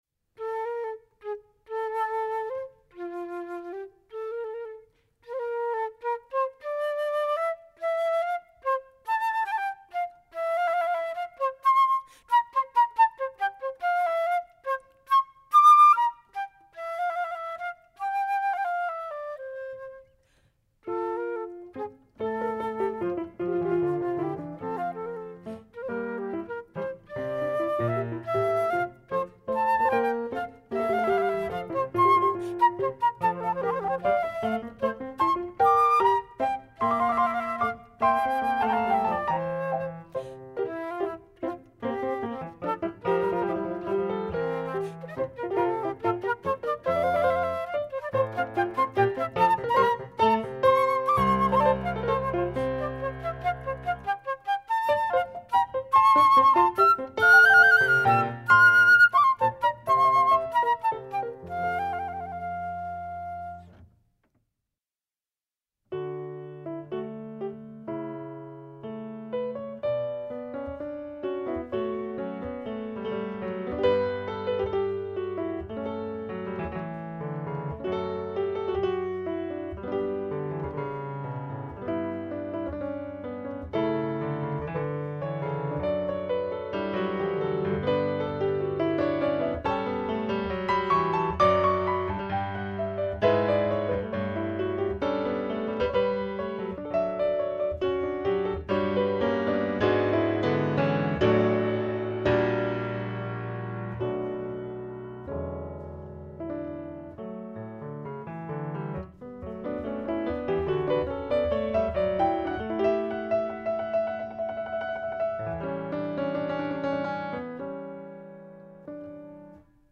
complemento del titolo: Nello stile galante.
mezzi di esecuzione: flauto, clavicembalo